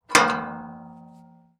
Metal_78.wav